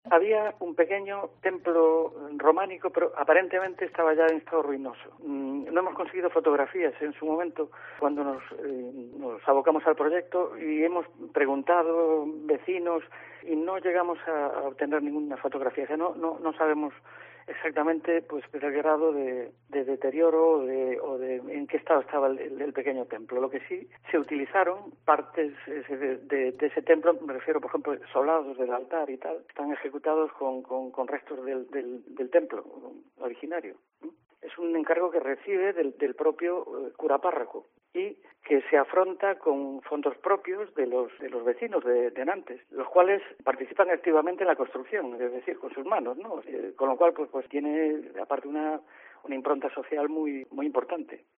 Declaracións a COPE Pontevedra